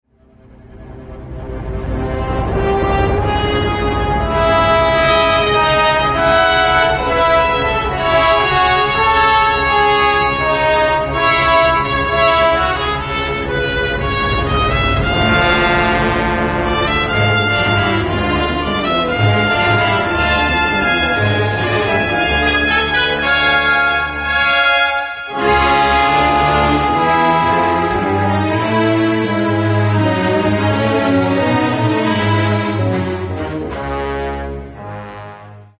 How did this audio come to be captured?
It has been remastered from the original tapes